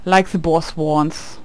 cudgel_ack2.wav